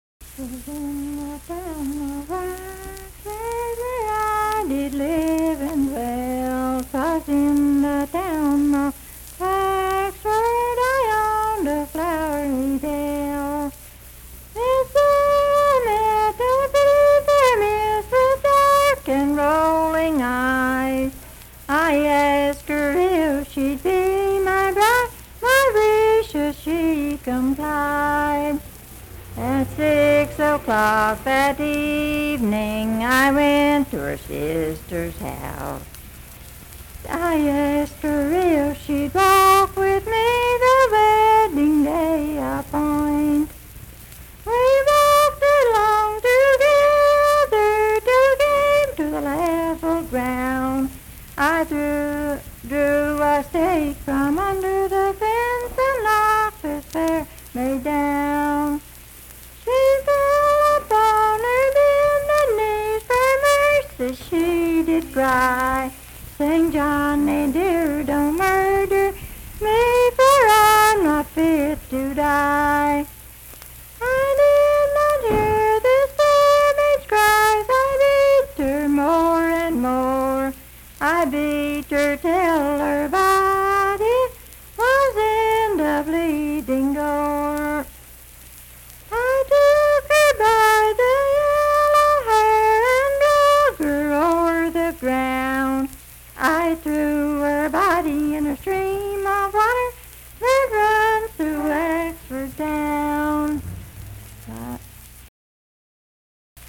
Unaccompanied vocal music
Verse-refrain 6(4).
Voice (sung)